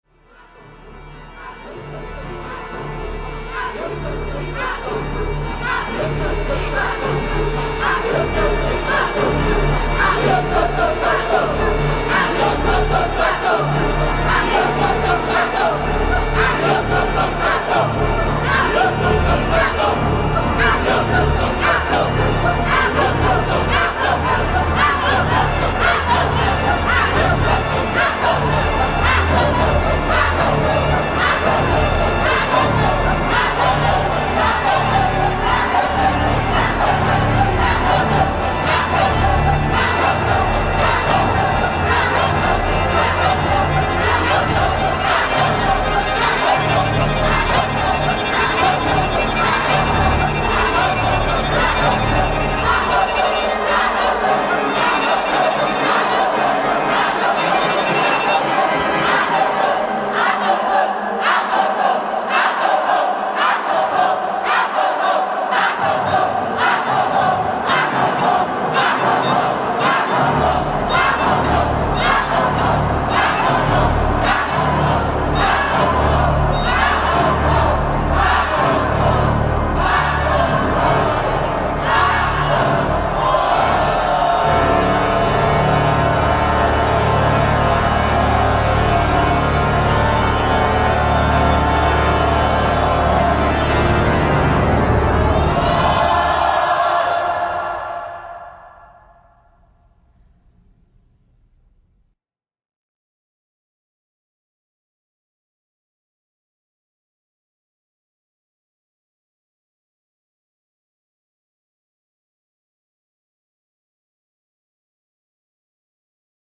completely improvised recording of music
Casavent Pipe Organ
Viola / Violin
Trumpet
Drum set
Live at st. Paul's, Toronto 2007